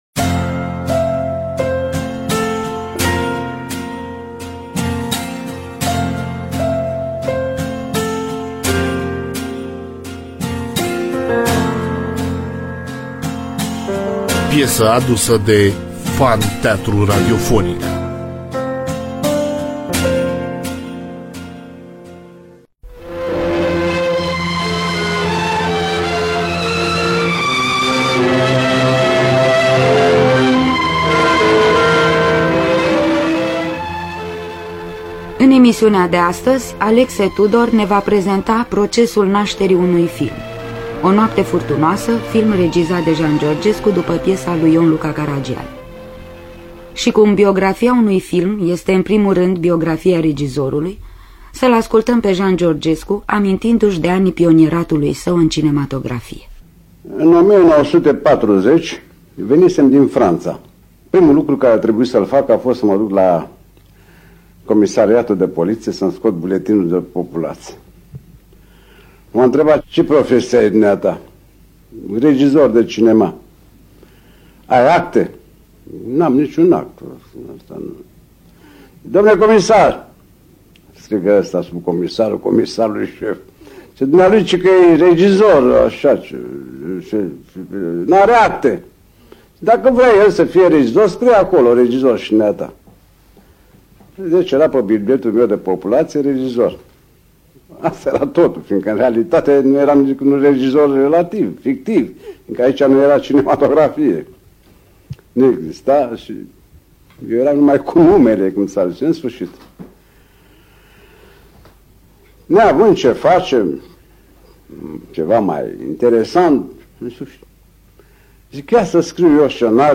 Fragmente din film